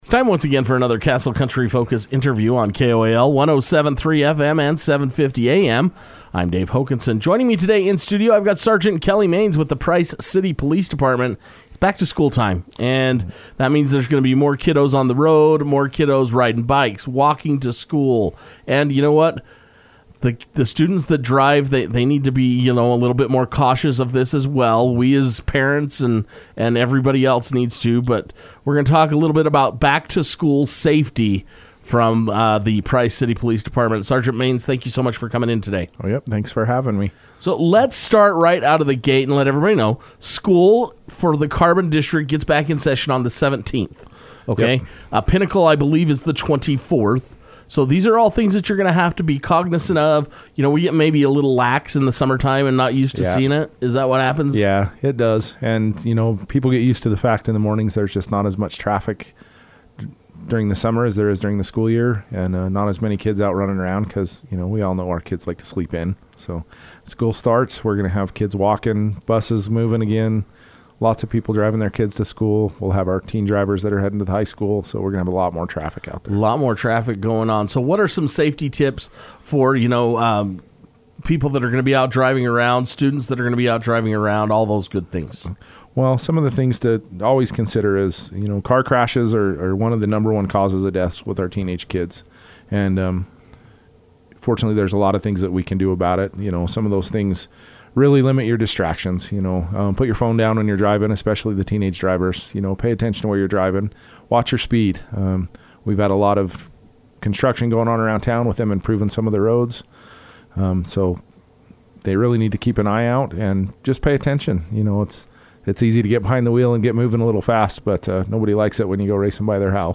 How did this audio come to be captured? The Price City Police Department are very busy keeping the community safe so it was nice that they were able to take some time from their busy schedule to come into Castle Country Radio to discuss Back to School Safety Tips.